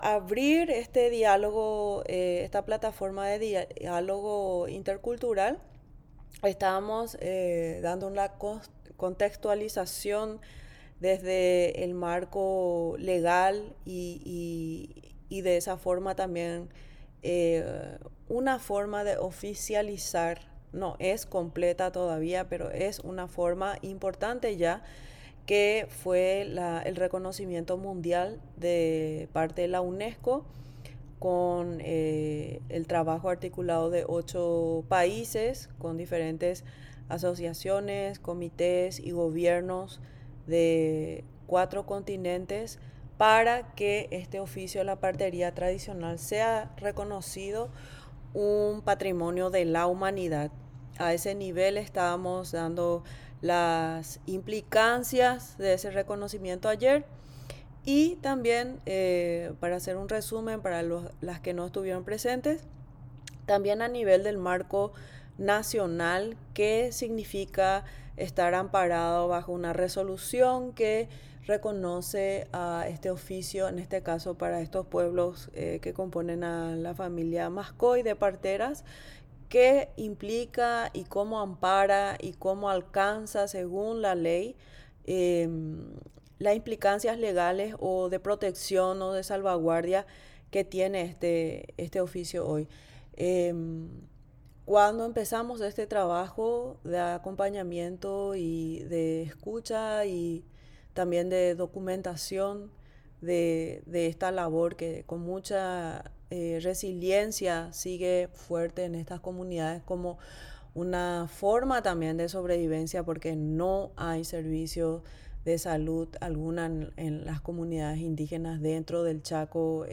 Zamuco Maria Auxiliadora, Carmelo Peralta, Alto Paraguay 2023 Mataguaya Maka Articulando e Construindo Saberes kuatiaa (Brasil) Serie del registro de la memoria oral En la comunidad de Ita Paso, Encarnaci ó n, Itap ú a se grabaron relatos en la lengua mak á (mataguaya) desde 2022 para fines de revitalizaci ó n y did á cticos.